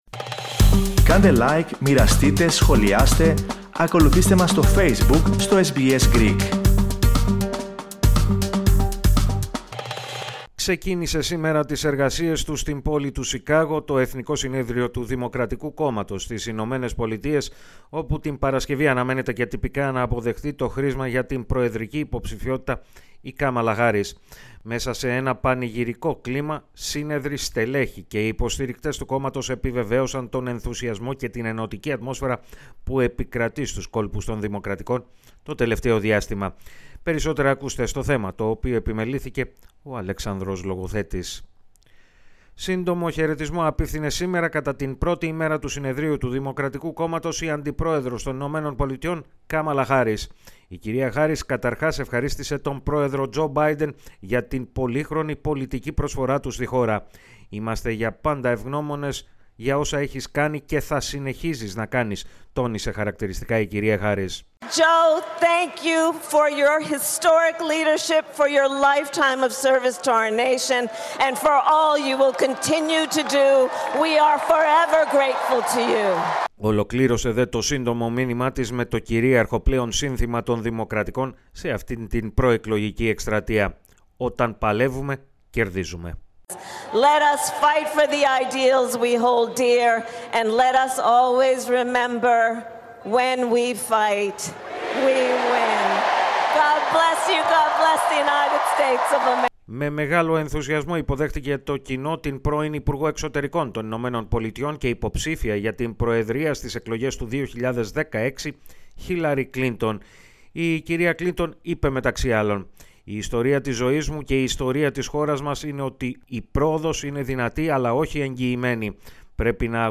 Συγκινητική ομιλία Μπάιντεν στο συνέδριο των Δημοκρατικών